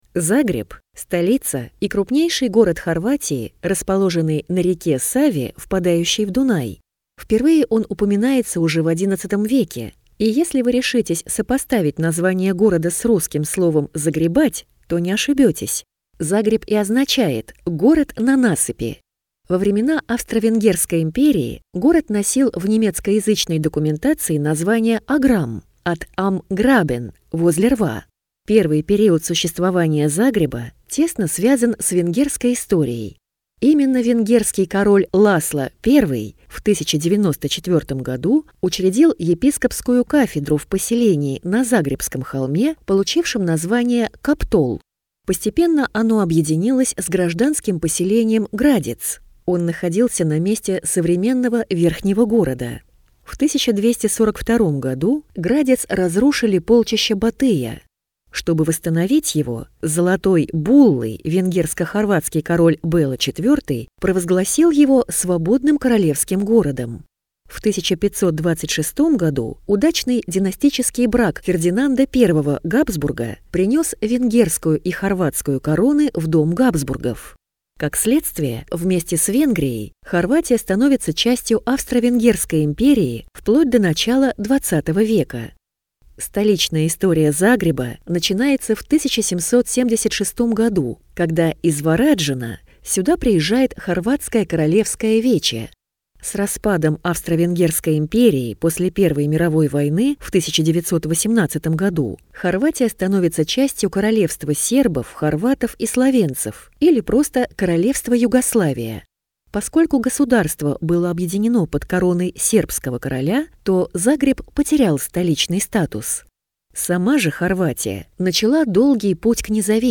Аудиокнига Загреб. Аудиогид | Библиотека аудиокниг